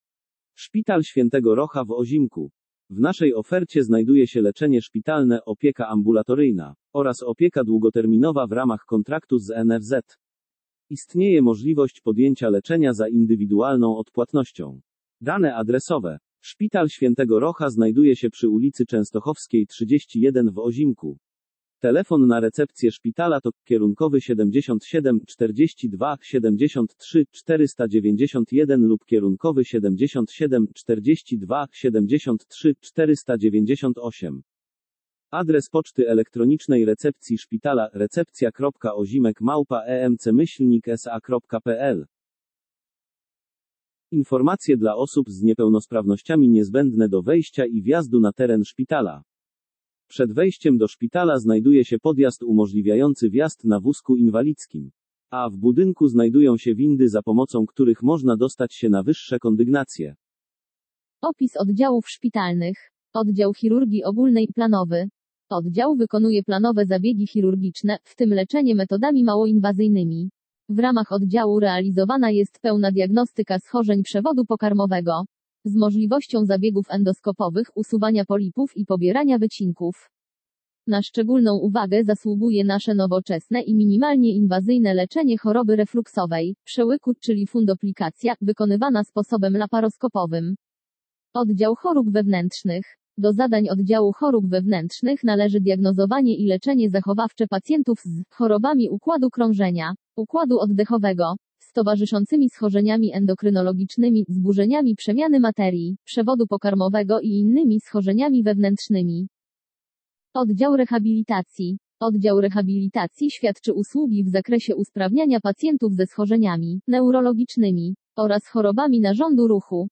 Informacja o Szpitalu św. Rocha w Ozimku w postaci pliku dźwiękowego - informacja do odsłuchania, odczytywana przez głos syntetyczny.
0733-tekst-odczytywany-przez-glos-syntetyczny.mp3